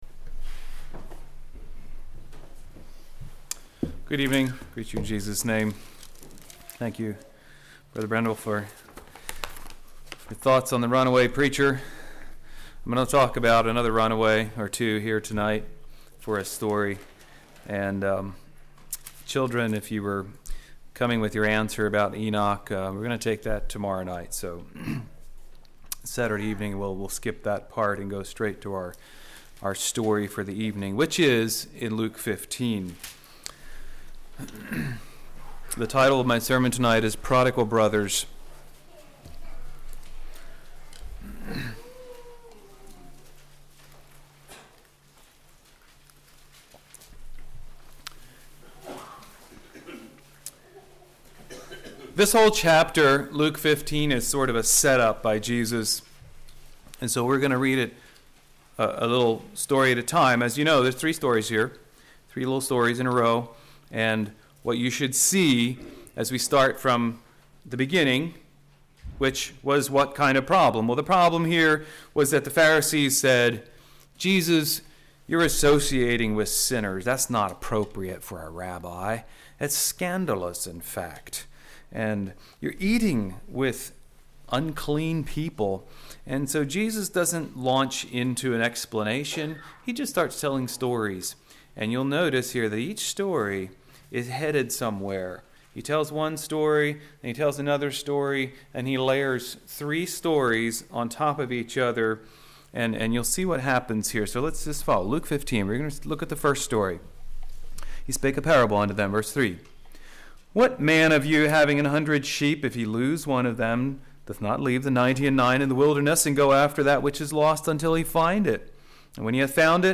Congregation: Elm Street